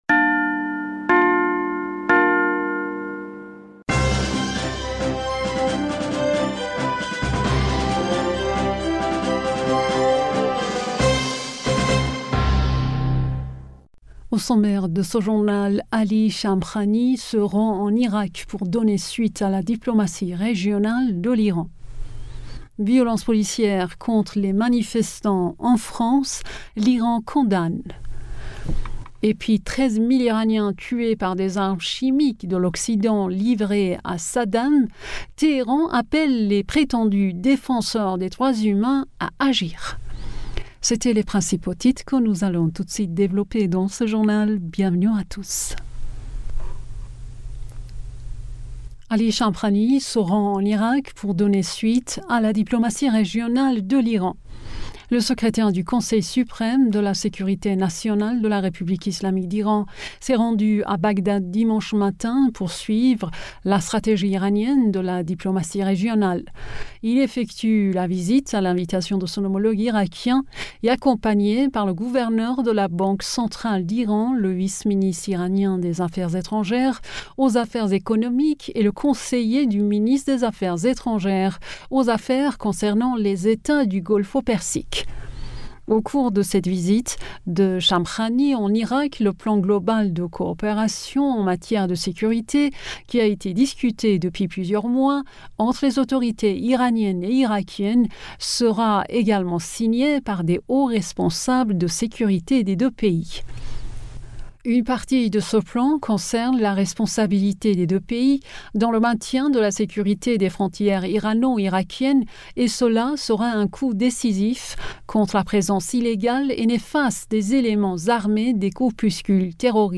Bulletin d'information du 19 Mars